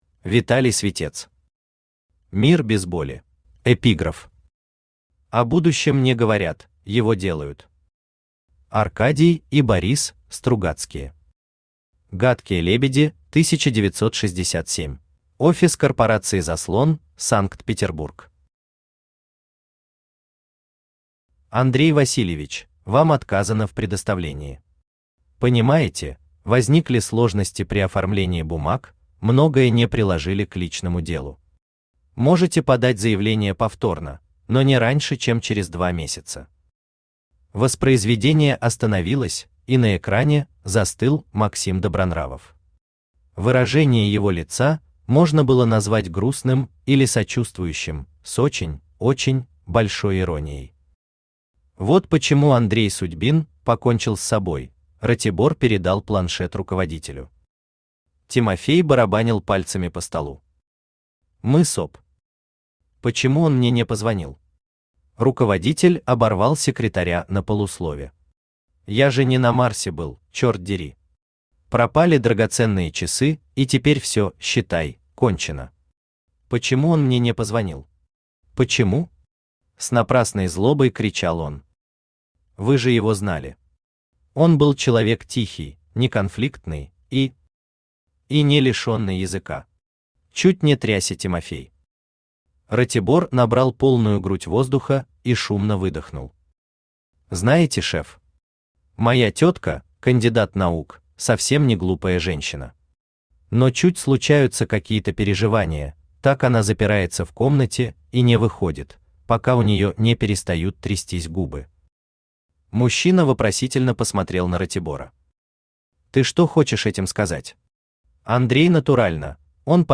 Aудиокнига Мир без боли Автор Виталий Святец Читает аудиокнигу Авточтец ЛитРес.